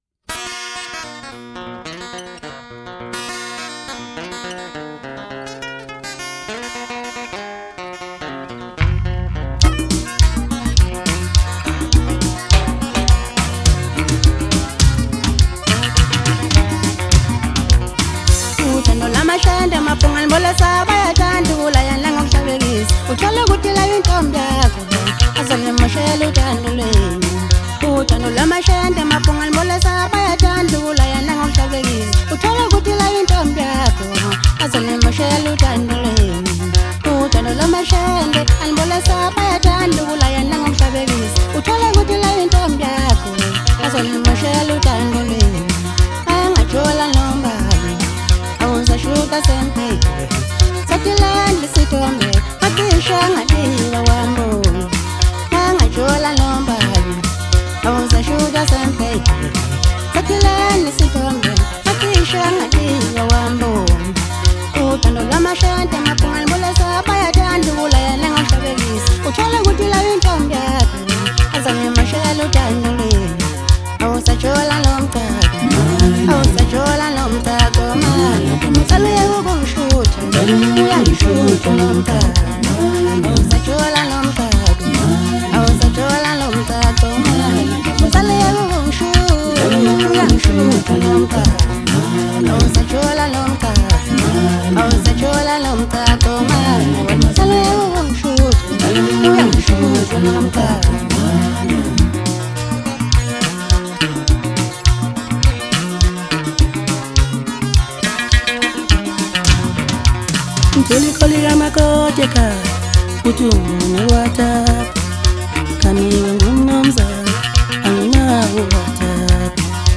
South African music duo who majors in the Maskandi genre